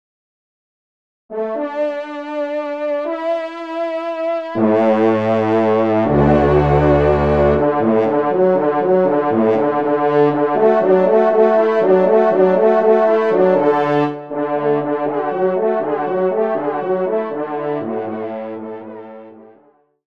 Genre : Fantaisie Liturgique pour quatre trompes
Pupitre de Basse